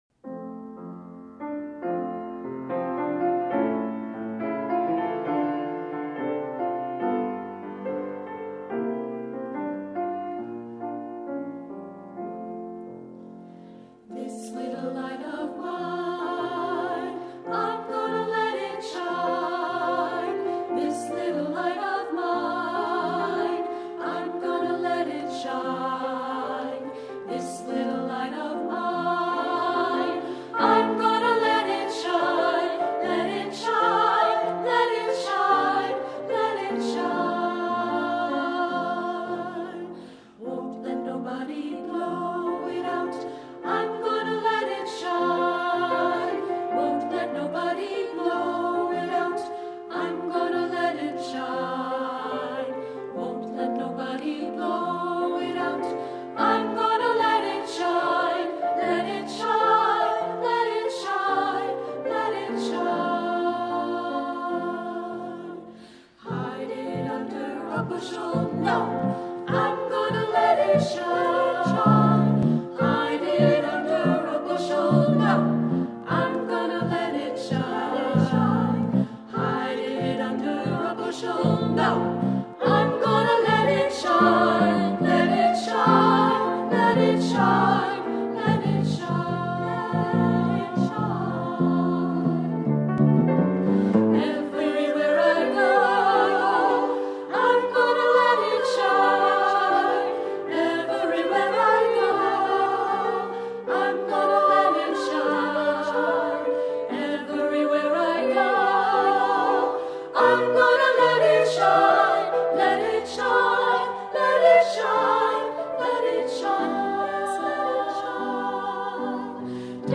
Accompaniment:      Piano, Bass Guitar